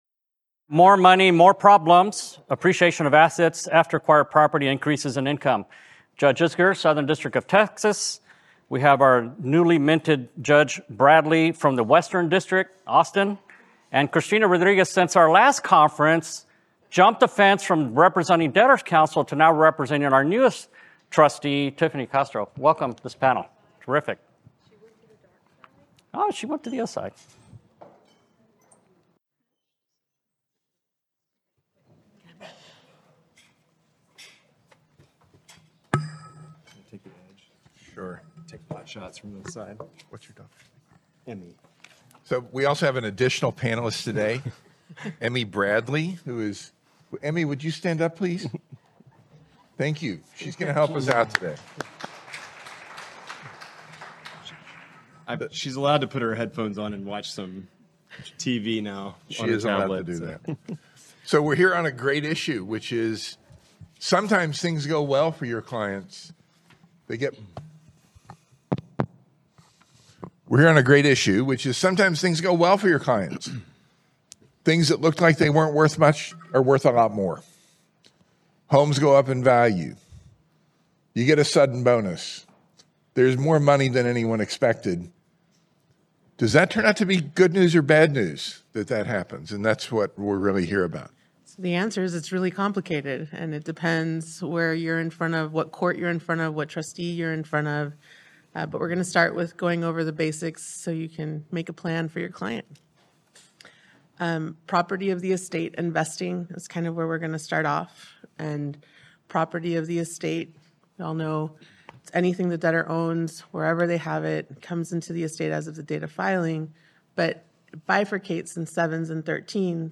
A discussion of a debtor’s obligation to disclose and the problems caused by appreciation, increased income and after acquired property in chapter 13, chapter 7 and conversions.